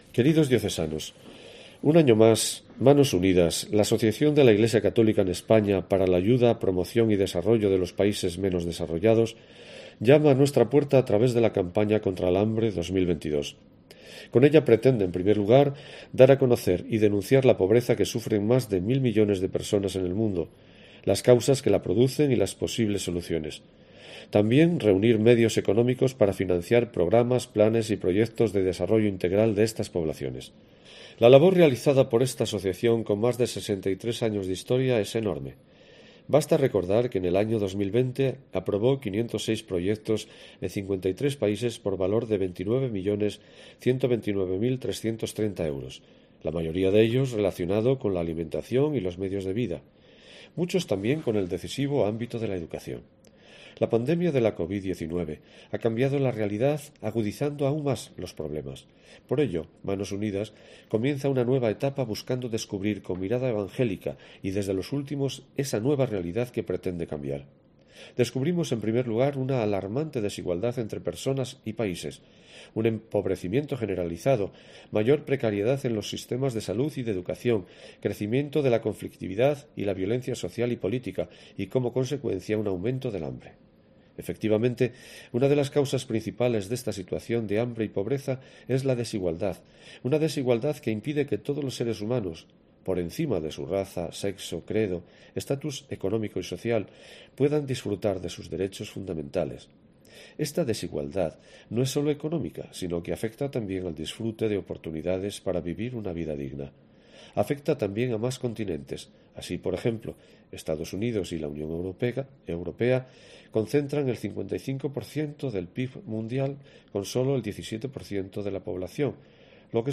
Escucha aquí la carta de esta semana del obispo de Astorga.